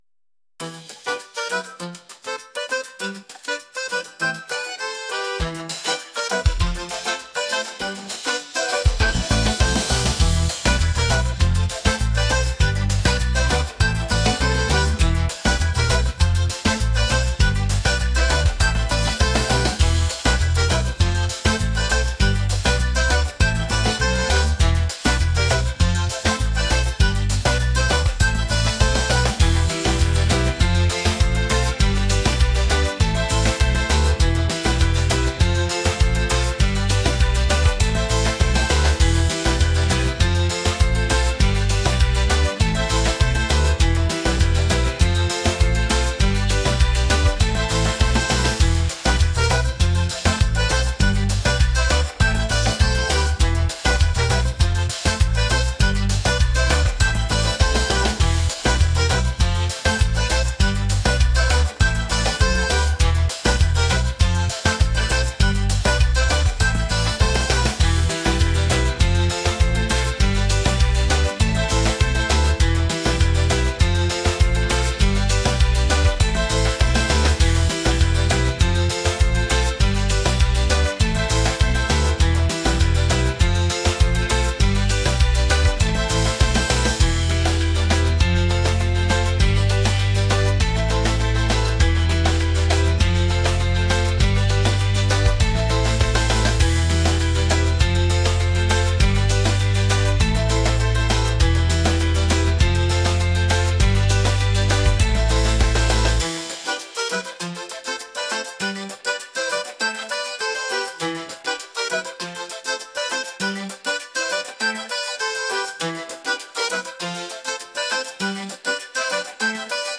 upbeat | pop